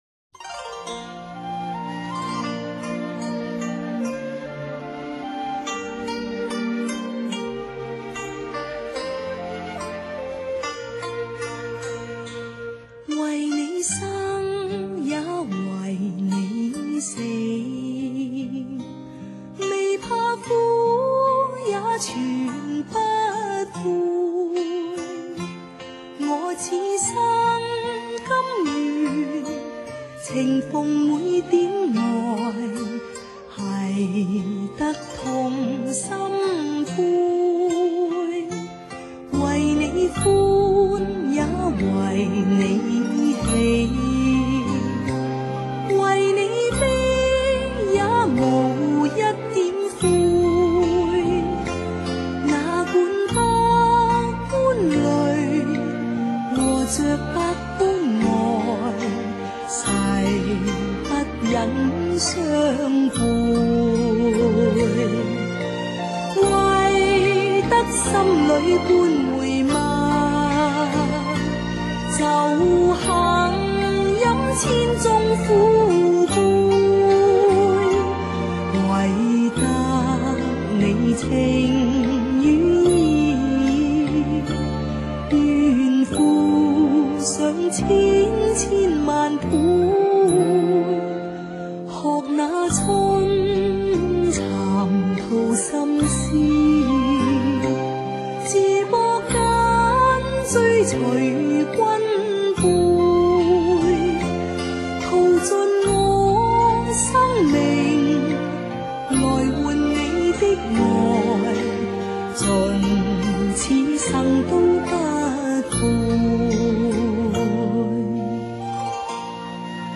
流传经年的抒情名曲